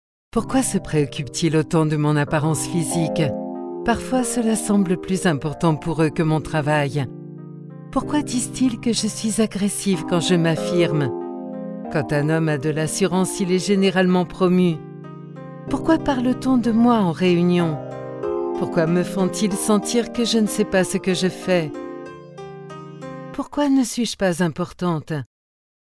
I am a french voice over Chic, Deep, Warm, Seductive a radio voice in FIP (Radio France) Voice over for documentaries on French TV : France 3, France 5, jimmy and ARTE Commercial, Corporate, E-learning, audiobook...
französisch
Sprechprobe: Industrie (Muttersprache):